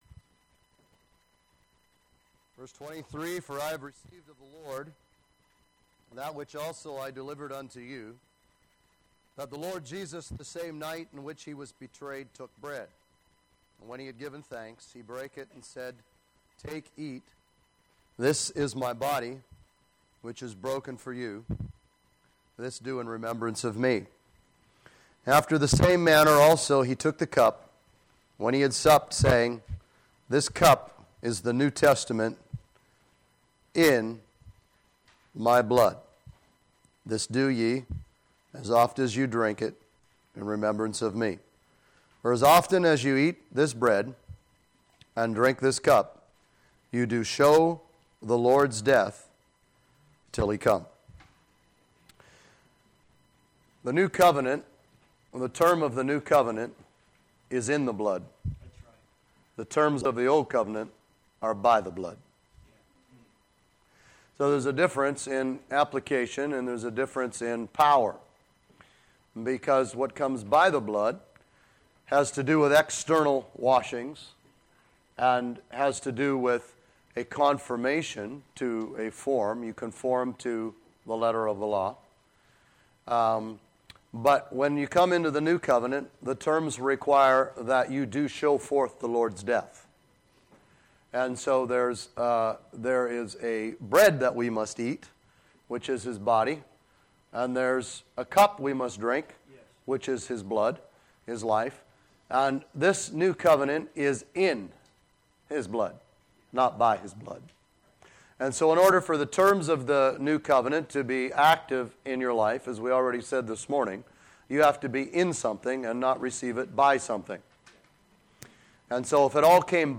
Posted in Teachings